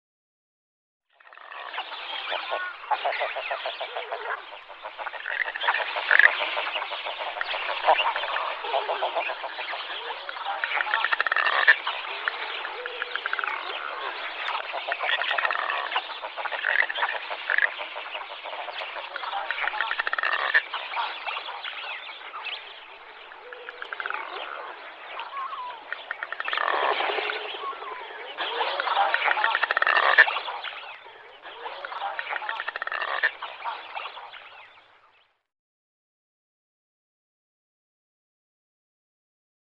Salskrake / Smew Mergellus albellus Läte / Sound Du är här > Fåglar / Birds > Salskrake / Smew Galleri med utvalda fågelbilder / Favourites Lund, januari 2024.
Salskrake.mp3